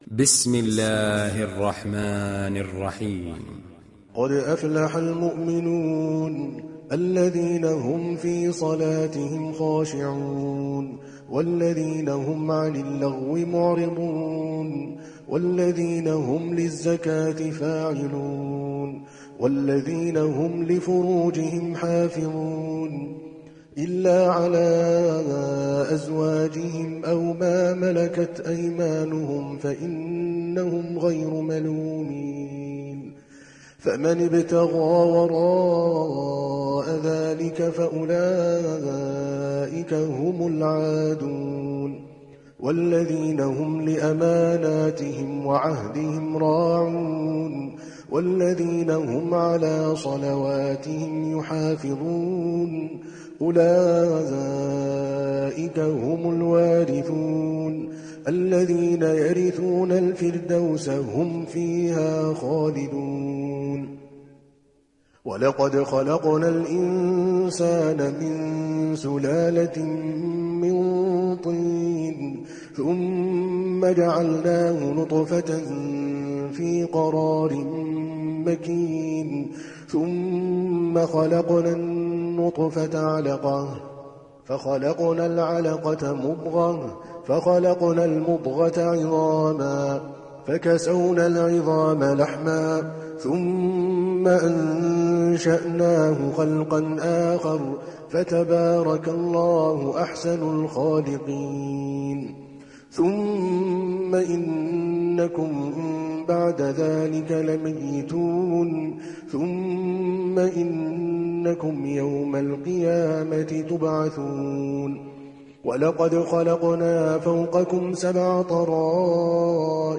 دانلود سوره المؤمنون mp3 عادل الكلباني (روایت حفص)